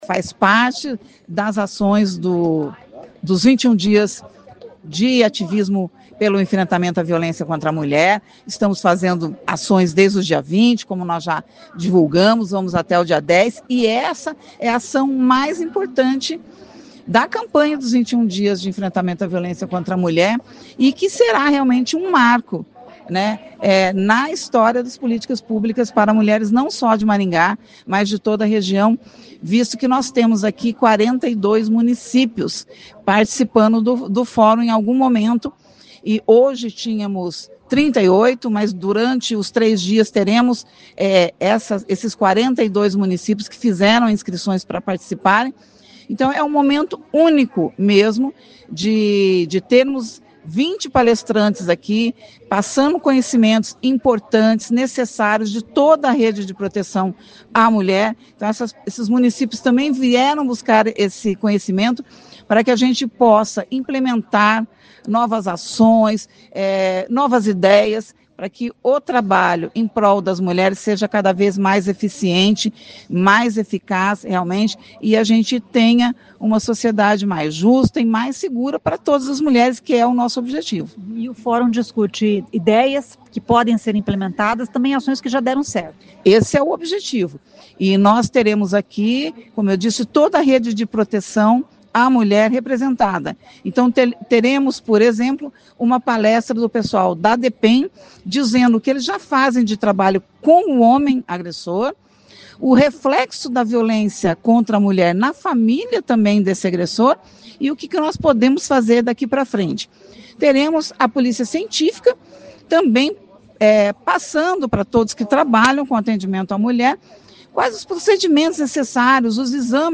Ouça o que diz a secretária da Mulher de Maringá Olga Agulhon.